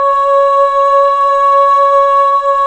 Index of /90_sSampleCDs/Club-50 - Foundations Roland/VOX_xFemale Ooz/VOX_xFm Ooz 1 S